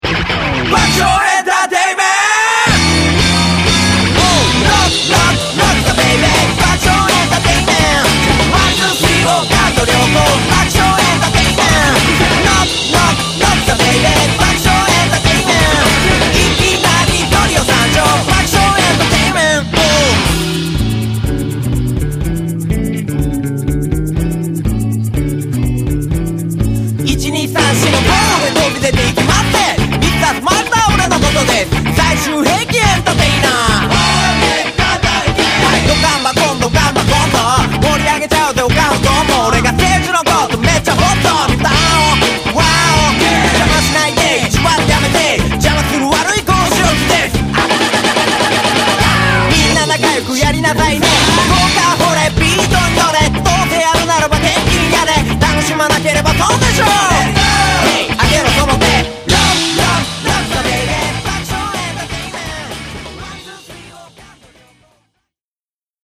借り物カルチャーじゃない等身大の日本語ヒップホップを感じます。
ボーカルのキャラのせいかな。
お笑いと音楽を融合させたそうですが、演奏自体もおもしろい。